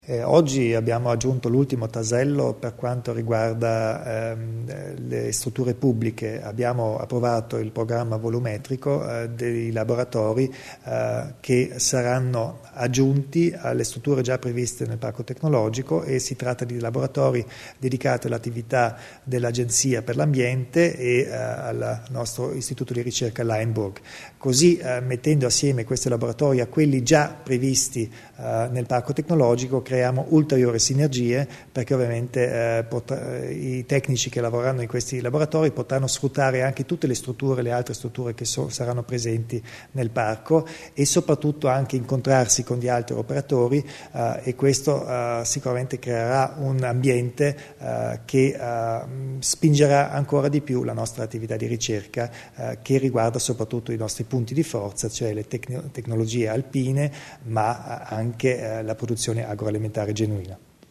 Il Presidente Kompatscher spiega l'importanza del nascente Parco Tecnologico